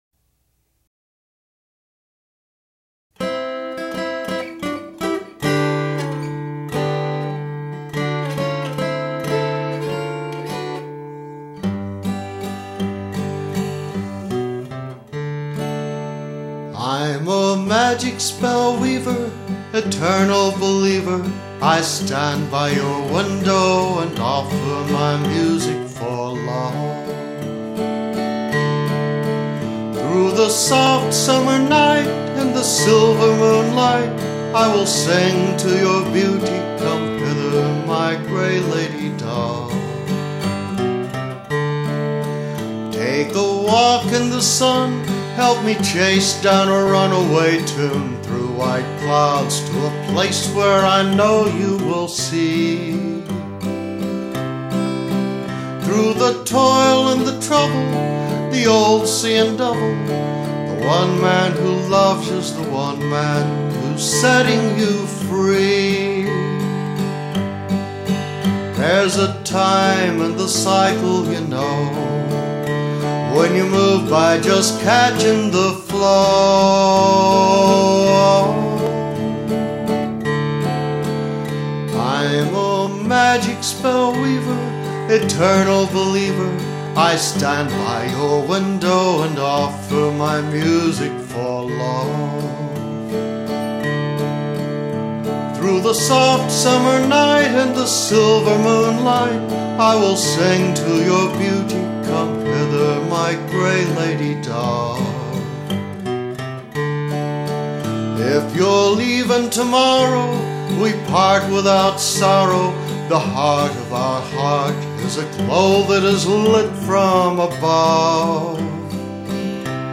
Just a love song, with a bit of a Mexican flavor.